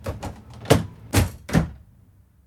tambdoorclose.ogg